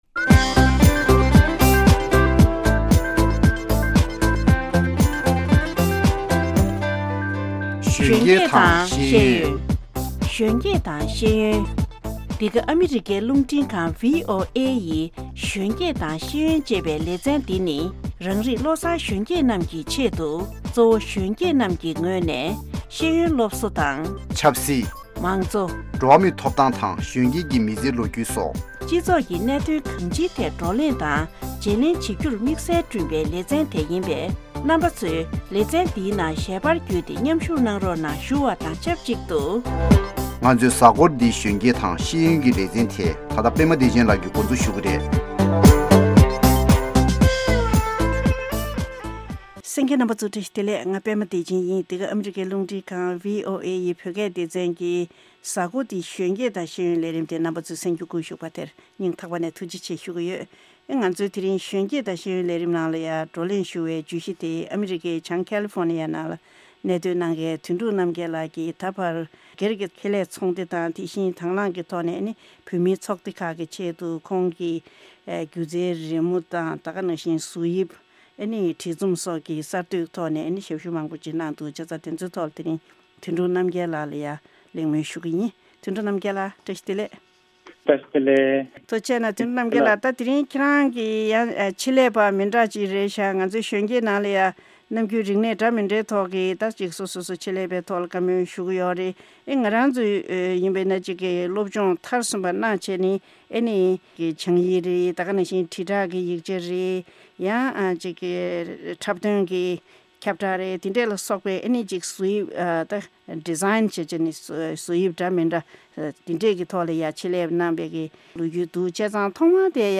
Interview with Graphic Designer.